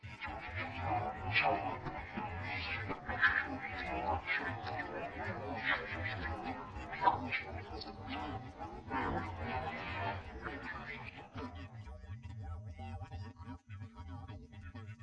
whispering2.wav